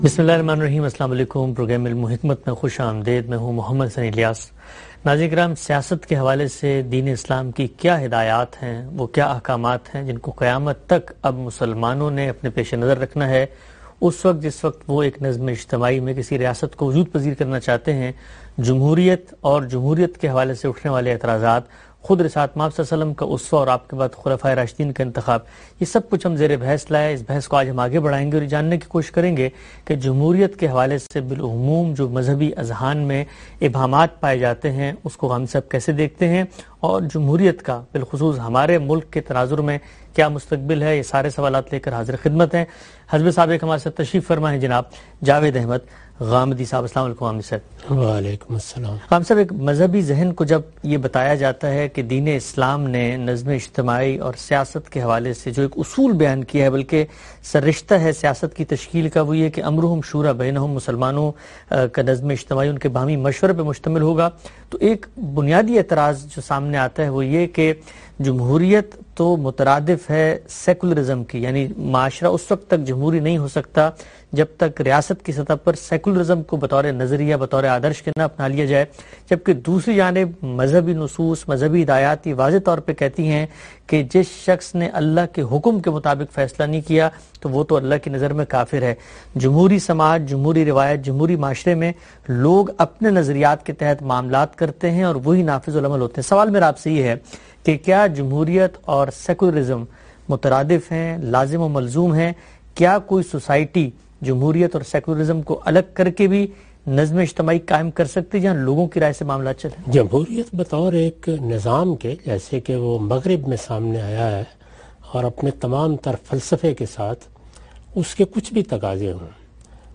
In this program Javed Ahmad Ghamidi answers the questions about "Islam and Democracy".